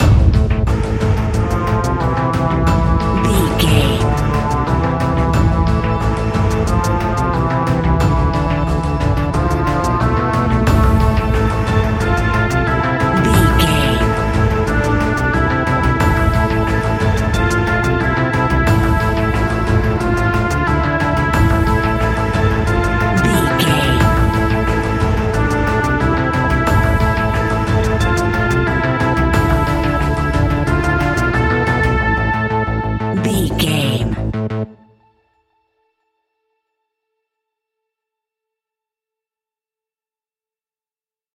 Aeolian/Minor
ominous
dark
haunting
eerie
synthesiser
drums
percussion
creepy
horror music